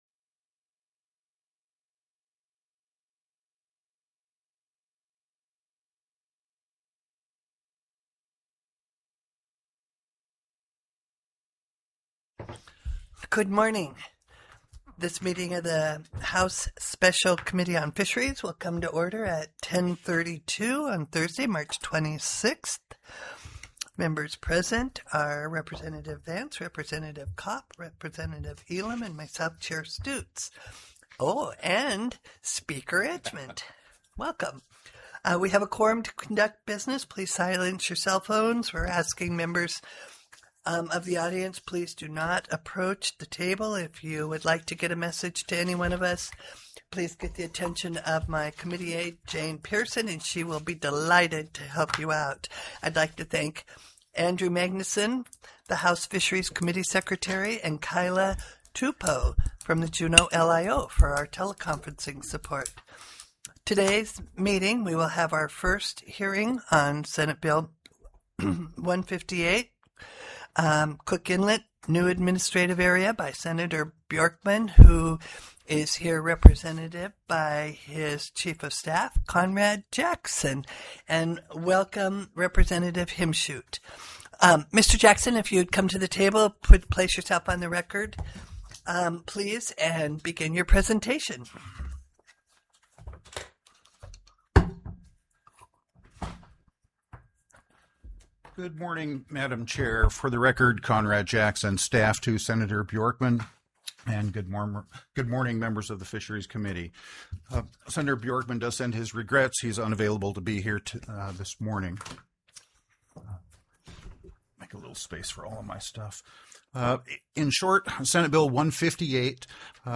The audio recordings are captured by our records offices as the official record of the meeting and will have more accurate timestamps.
SB 158 COOK INLET: NEW ADMIN AREA TELECONFERENCED Heard & Held